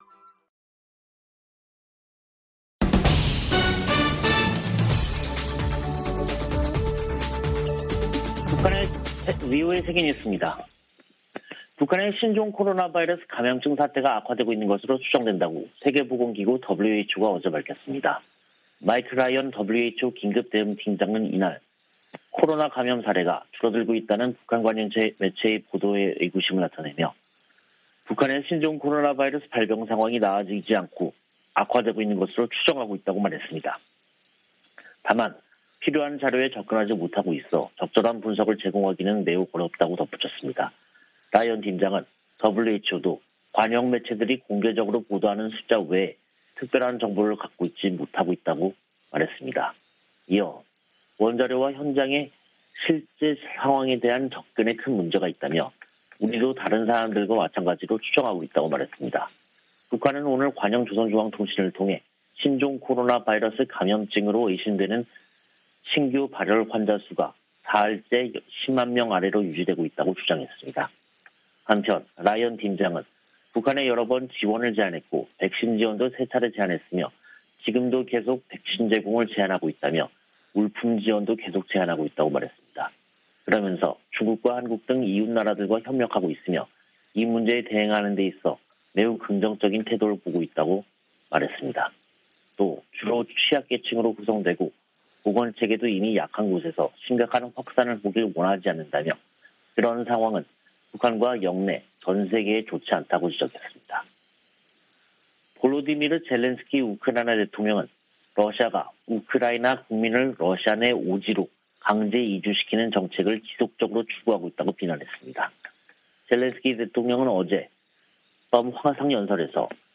VOA 한국어 간판 뉴스 프로그램 '뉴스 투데이', 2022년 6월 2일 2부 방송입니다. 북한의 7차 핵실험 준비 동향이 잇따라 포착되고 있는 가운데 3일 미·한·일 북 핵 수석대표들이 서울에 모여 대책을 논의합니다. 토니 블링컨 미 국무장관은 중국이 국제 현안들을 해결하는데 중요한 역할을 해야 한다며 그 중 하나로 북한 핵 문제를 꼽았습니다. 주한미군사령관과 일본 자위대 수장이 긴급 회동했습니다.